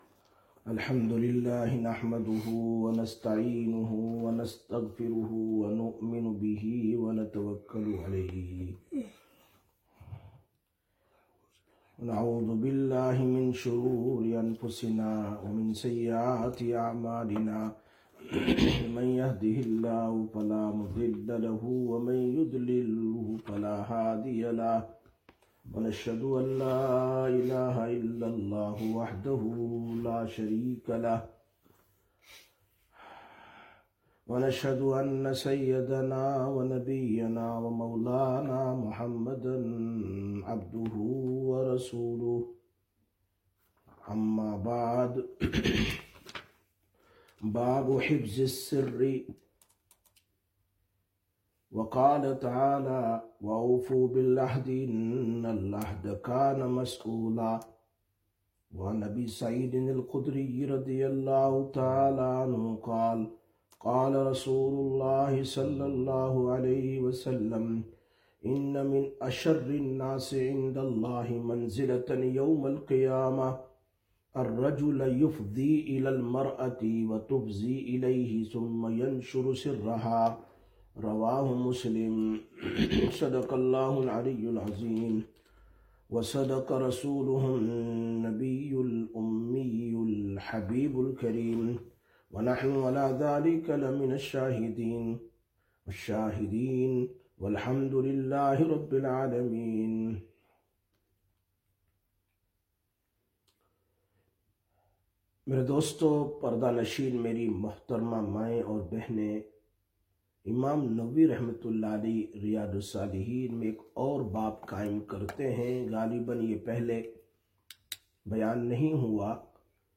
03/09/2025 Sisters Bayan, Masjid Quba